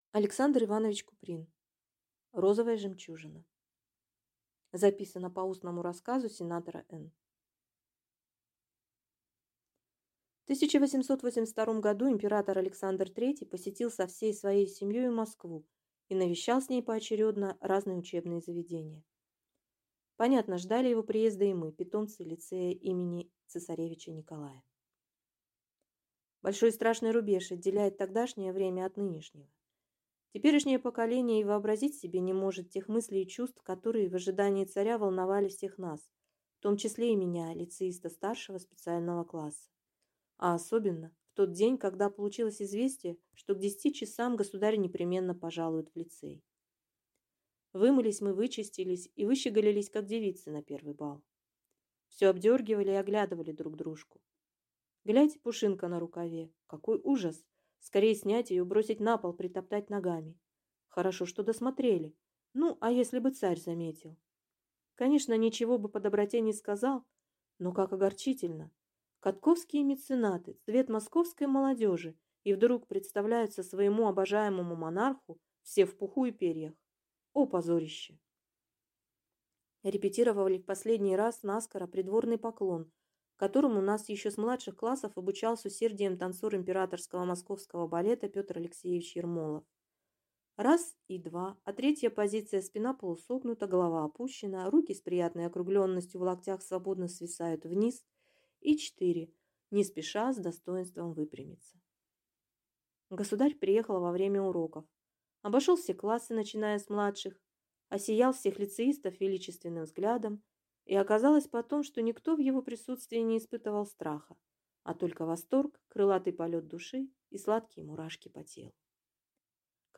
Аудиокнига Розовая жемчужина | Библиотека аудиокниг